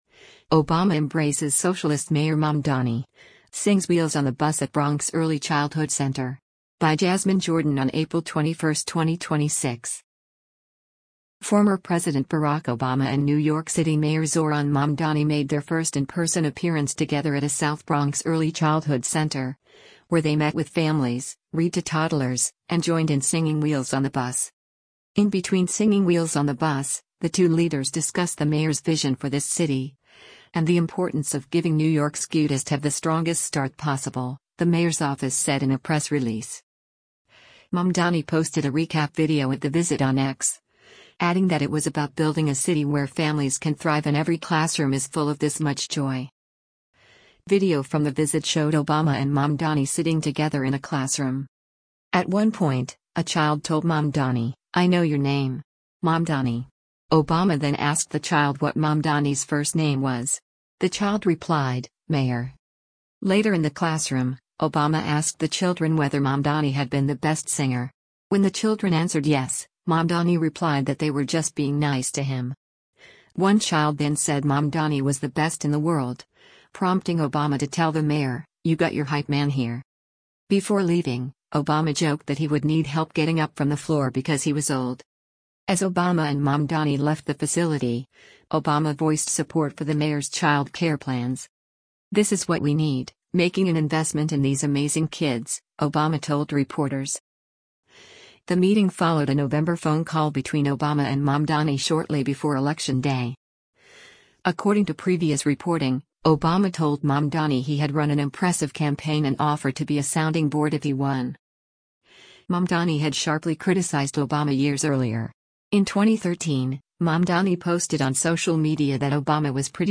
Obama and Mamdani Read, Sing with Children in South Bronx
Former President Barack Obama and New York City Mayor Zohran Mamdani made their first in-person appearance together at a South Bronx early childhood center, where they met with families, read to toddlers, and joined in singing “Wheels on the Bus.”
Video from the visit showed Obama and Mamdani sitting together in a classroom.